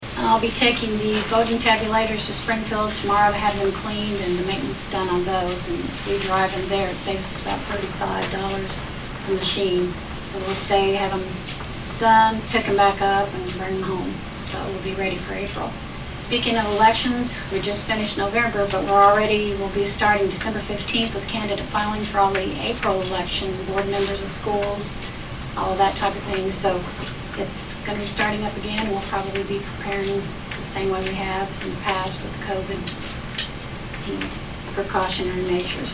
During the meeting of the county commission, Clerk Debbie Russell explained.